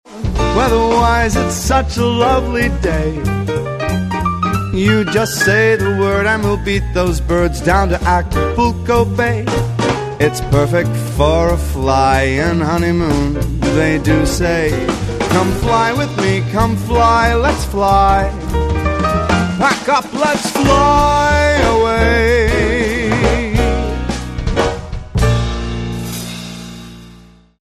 Keyboard,   Bass,   Drums,   Vocals
Pack up and come fly away with the best swing band around.
sit back and relax to the smooth crooning vocals
Big Band